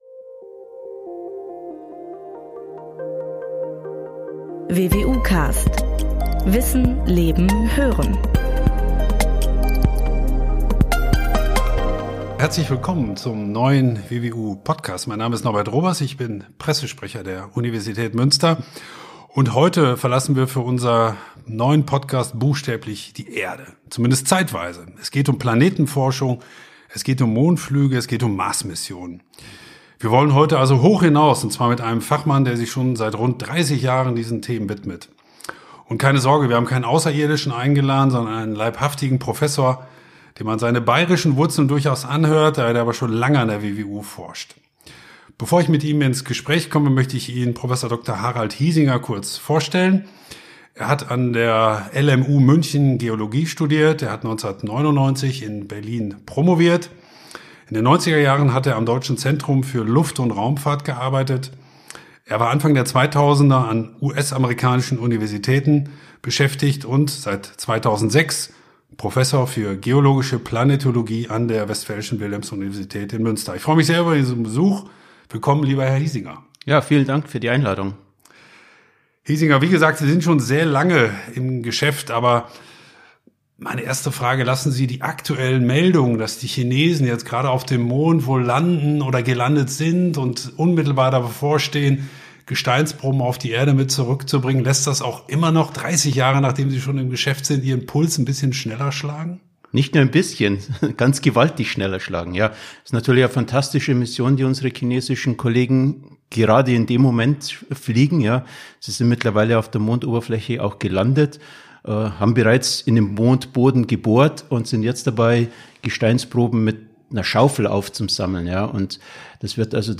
Dem Mond auf der Spur Interview